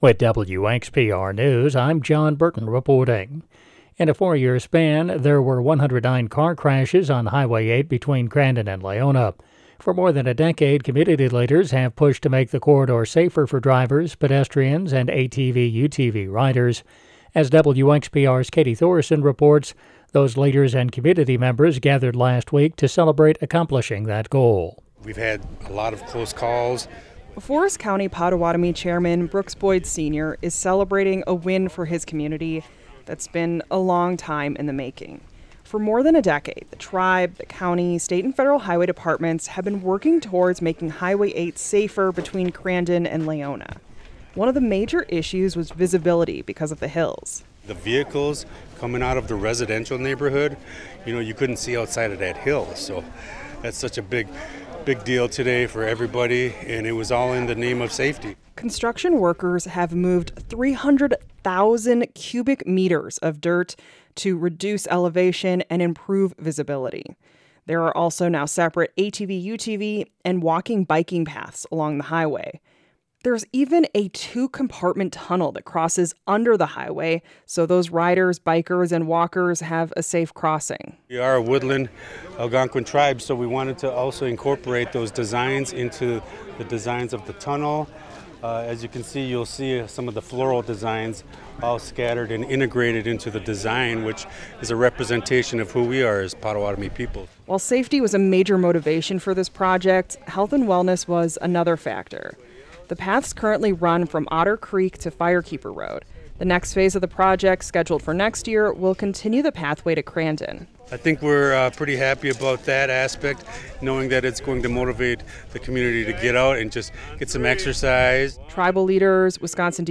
The Extra is WXPR’s daily news podcast.